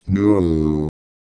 Worms speechbanks
Nooo.wav